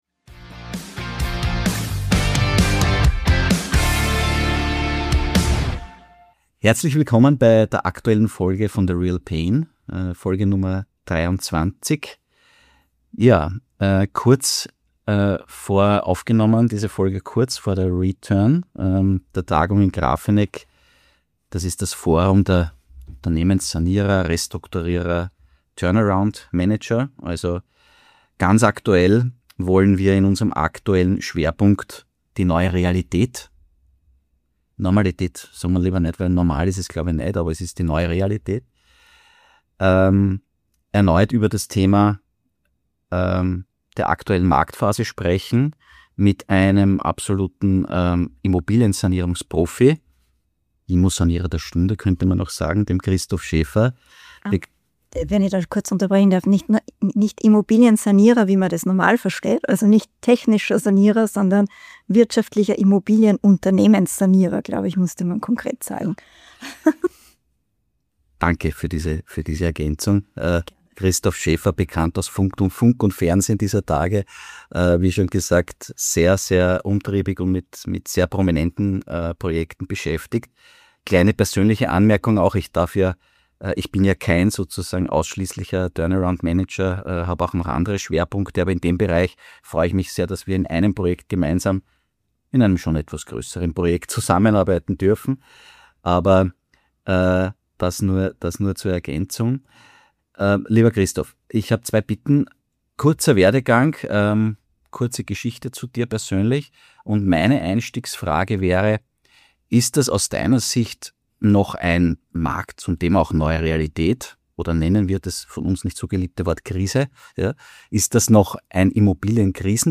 Im Fokus stehen die aktuelle Marktphase, der Umgang mit notleidenden Projekten und die Frage, wie sich durch strukturierte Prozesse bessere Ergebnisse erzielen lassen als im Insolvenz- oder Fire-Sale-Szenario. Ein Gespräch über Marktmechanik, Zusammenarbeit zwischen Banken und Eigentümern – und warum frühzeitiges Handeln entscheidend ist.